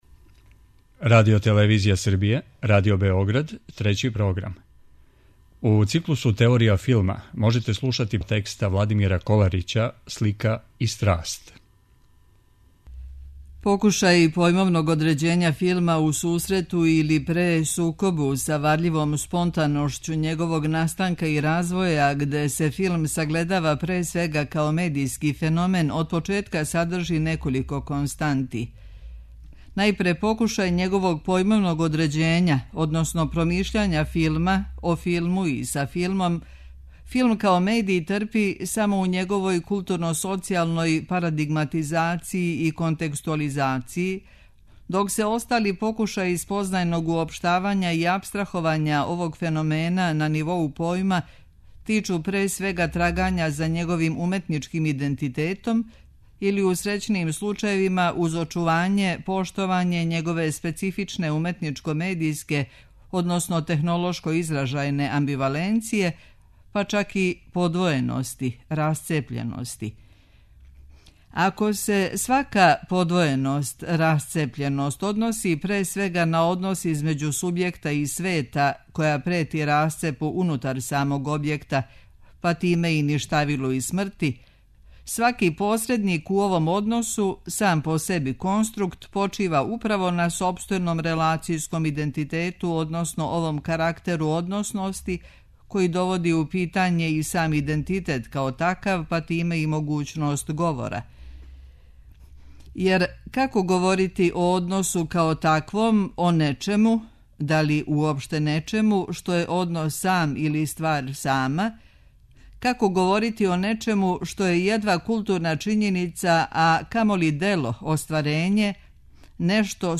У циклусу ТЕОРИЈА ФИЛМА, од четвртка 7. до суботе 9. августа, можете слушати текст Владимира Коларића 'Слика и страст: испитивање филма'.